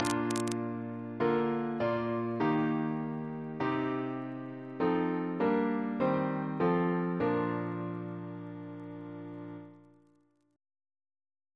Single chant in B♭ Composer: William Russell (1777-1813) Reference psalters: OCB: 87